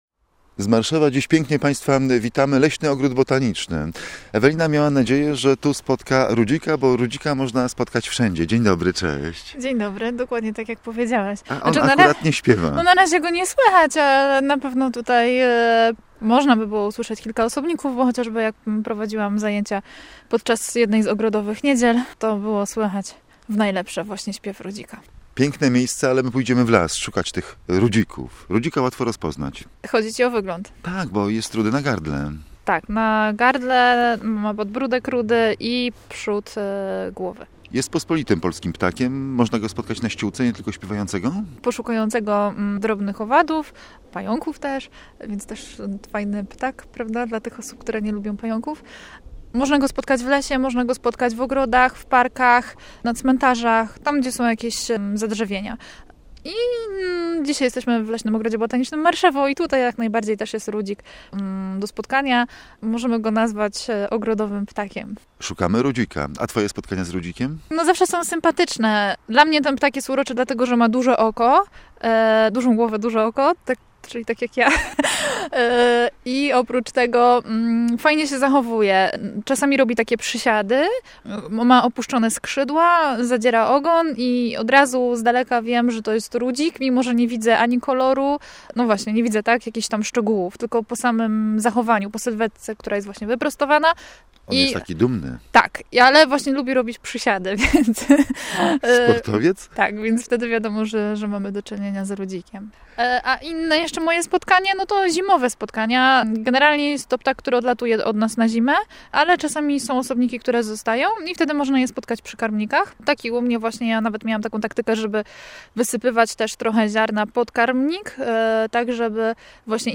Rudzika szukamy w Leśnym Ogrodzie Botanicznym w Marszewie.
My na początku spotkania nie mamy tyle szczęścia, zaczynamy więc od opisu wyglądu ptaka.
pTAK-rudzik.mp3